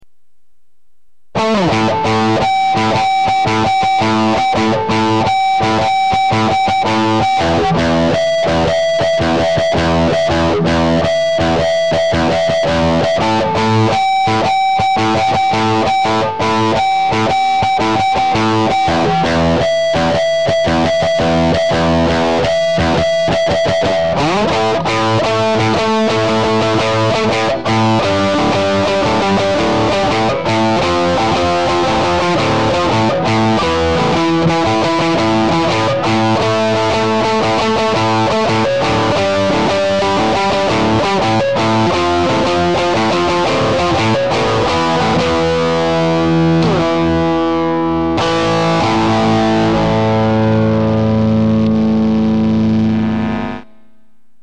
Mozda je malkice preglasno...